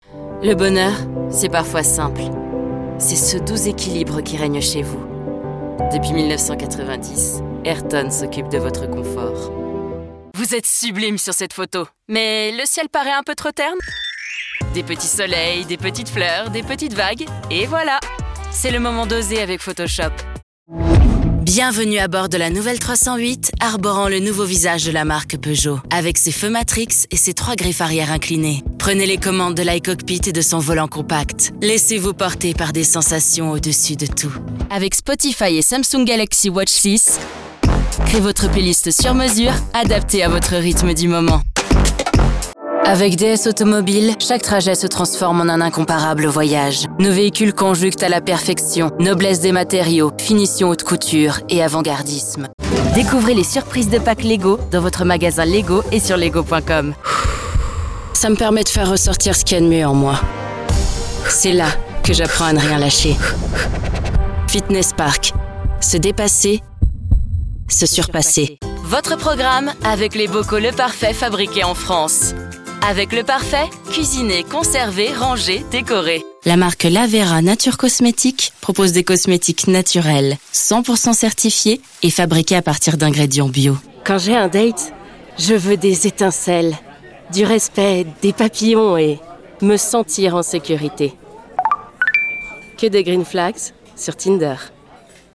Natural, Versátil, Amable
Comercial